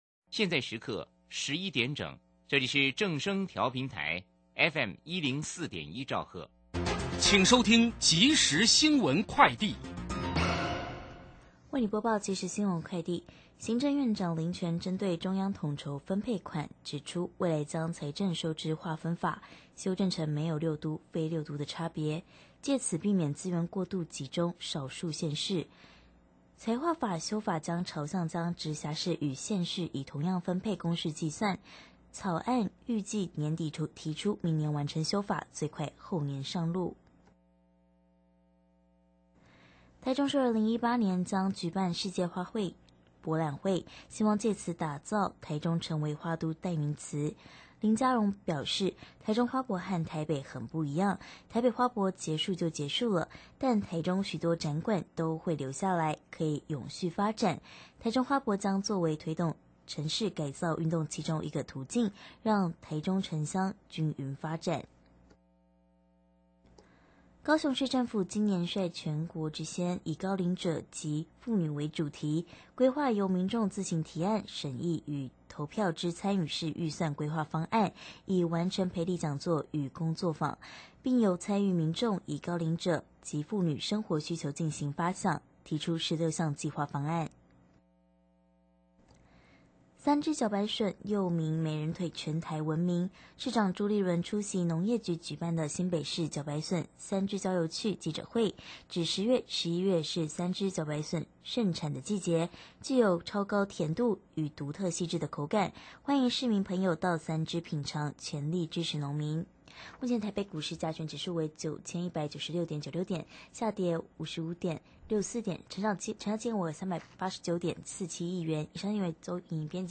受訪者： 台北地檢許祥珍主任檢察官 節目內容： 續談羈押與交保何謂停羈押？